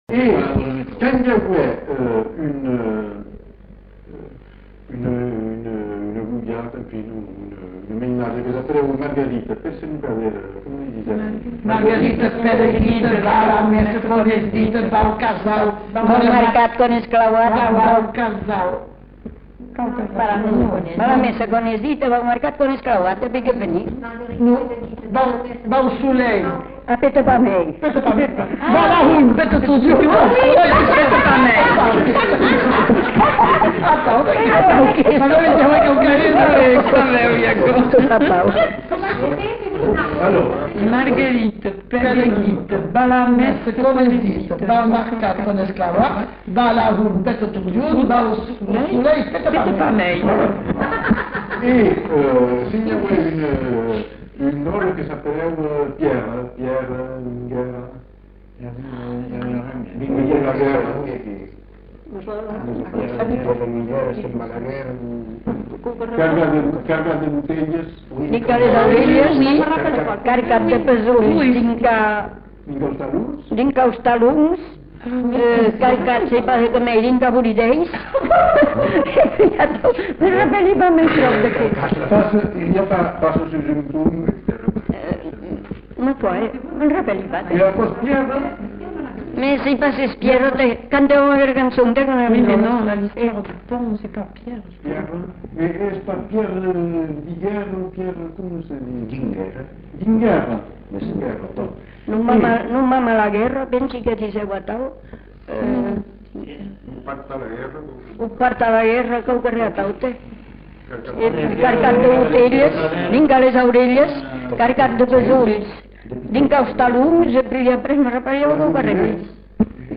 Lieu : Villandraut
Genre : forme brève
Effectif : 3
Type de voix : voix de femme
Production du son : récité
Classification : formulette enfantine